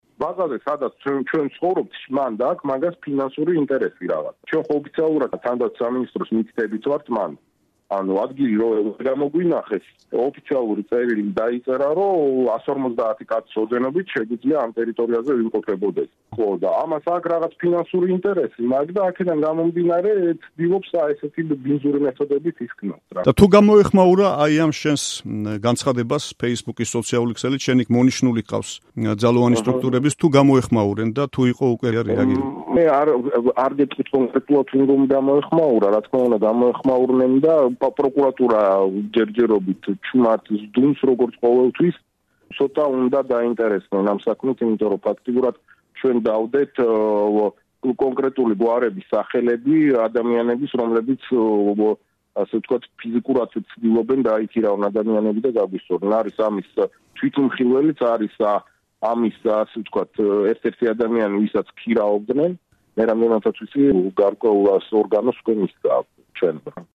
სატელეფონო ინტერვიუ